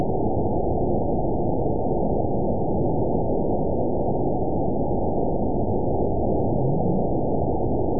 event 920094 date 02/22/24 time 12:16:01 GMT (1 year, 2 months ago) score 9.22 location TSS-AB03 detected by nrw target species NRW annotations +NRW Spectrogram: Frequency (kHz) vs. Time (s) audio not available .wav